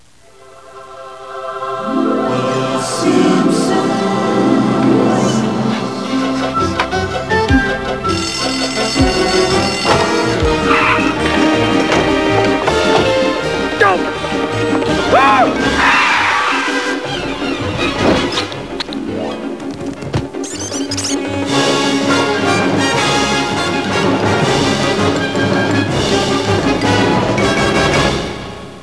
4) Opening Theme